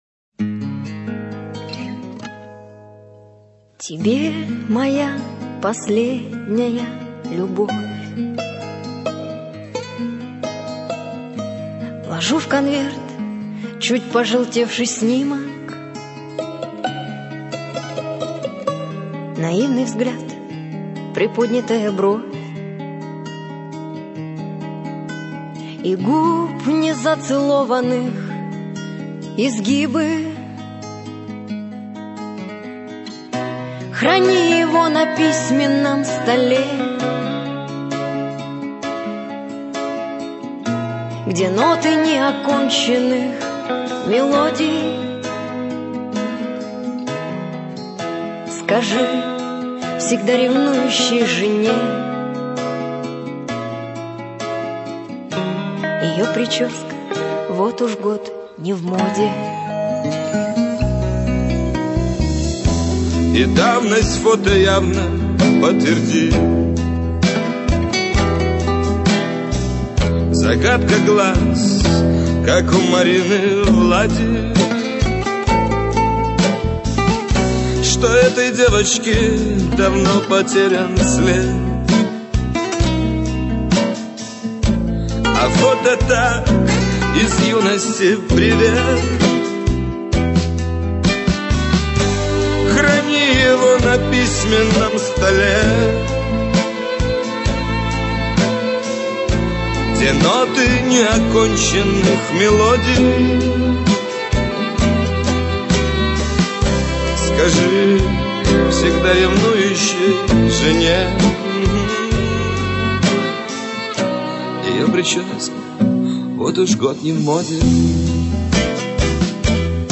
/64kbps) Описание: Поет вместе со своей женой.